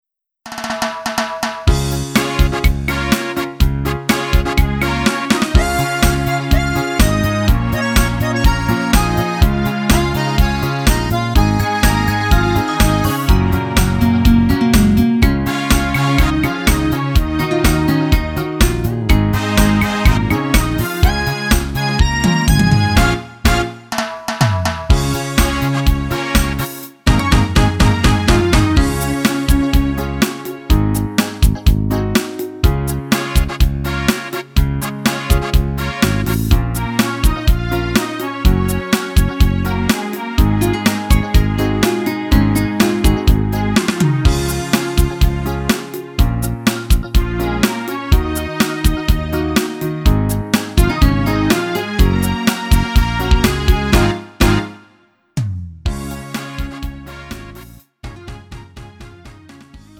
음정 원키 3:29
장르 가요 구분 Lite MR